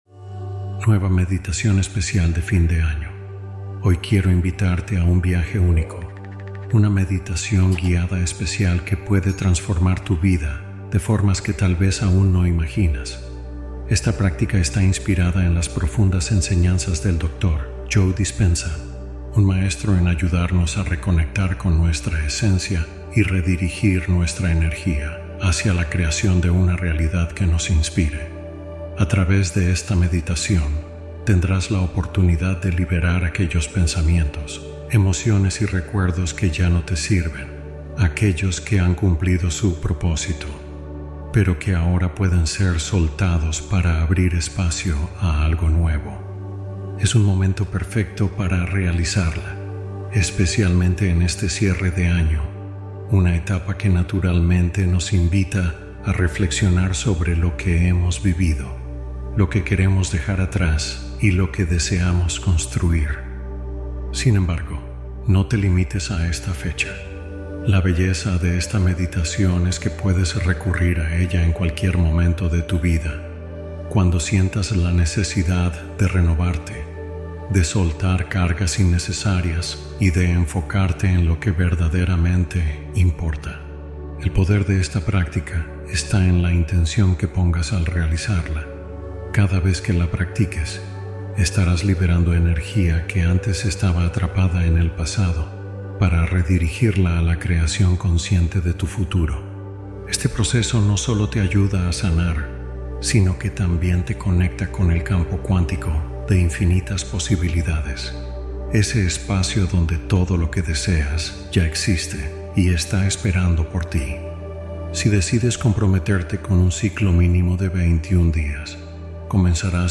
Meditación de Año Nuevo Para Crear una Nueva Vida